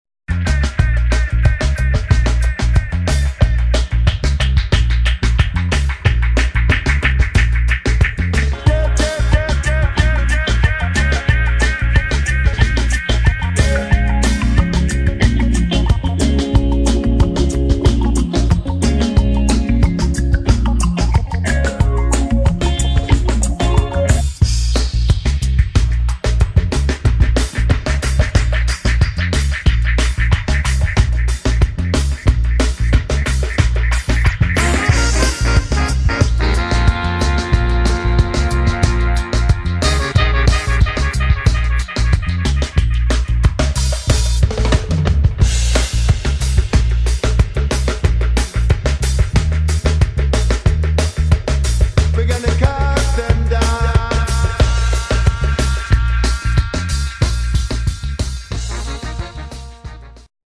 [ AFRO DUB / BROKEN BEAT ]